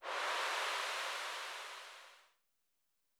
weatherLight.wav